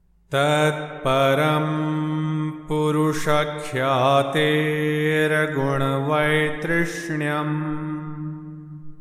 | Chant Yoga Sutra 1.16